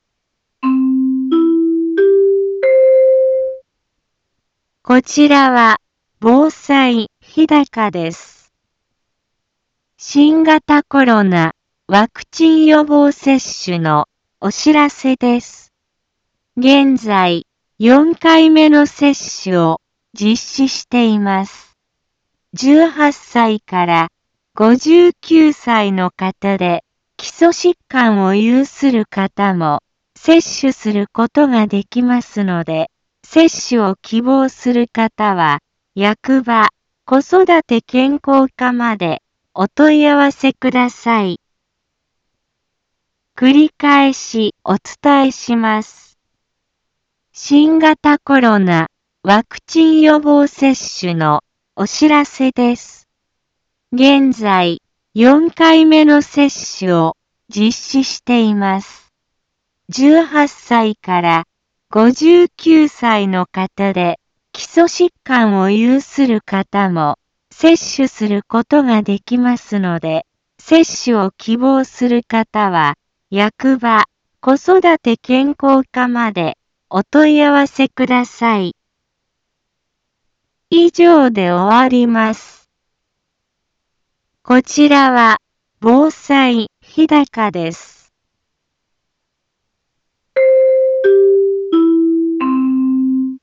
Back Home 一般放送情報 音声放送 再生 一般放送情報 登録日時：2022-07-25 15:03:18 タイトル：新型コロナワクチン予防接種のお知らせ インフォメーション：こちらは防災日高です。